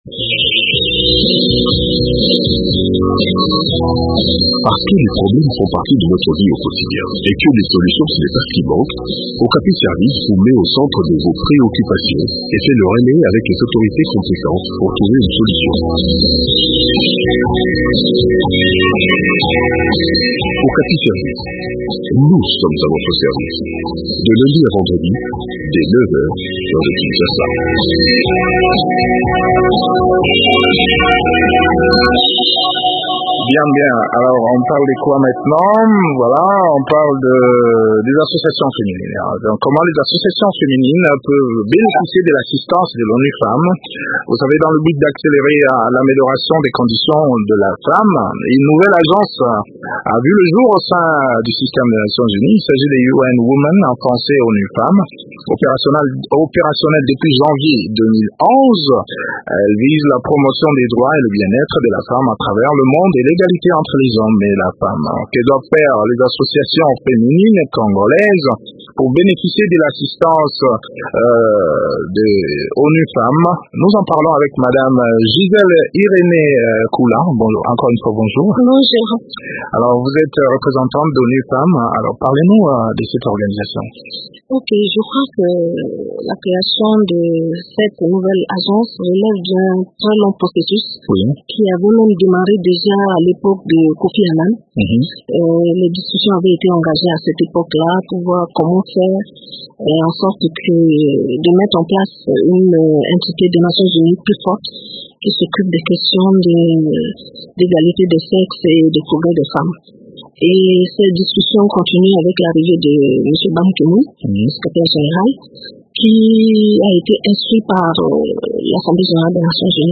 Que doivent faire les ONG pour bénéficier de l’assistance de UN Women ? Eléments de réponse dans cet entretien